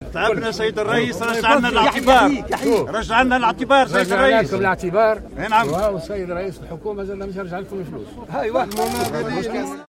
قال رئيس الجمهورية الباجي قائد السبسي اليوم الأحد 15 أكتوبر 2017، خلال إشرافه بمدينة بنزرت على موكب إحياء الذكرى 54 لعيد الجلاء، إن الدولة سترد الاعتبار لكل المقاومين والمناضلين.